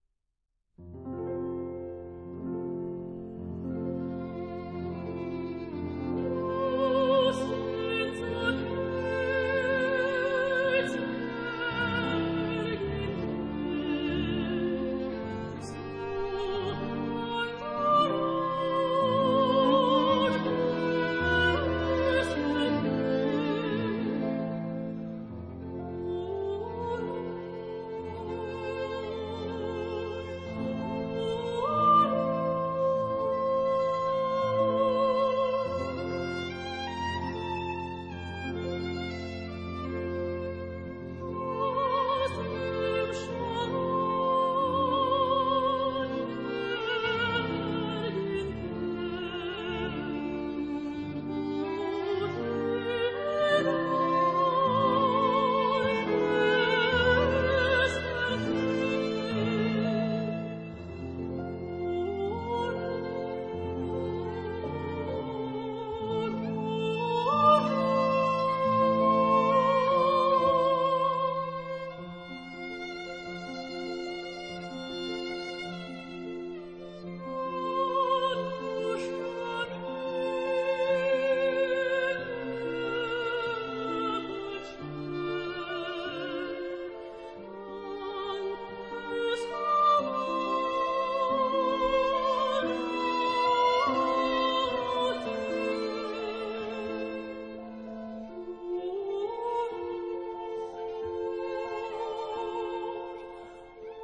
說她清澈的嗓音、存粹的音調、嚴格控制的顫音與技藝，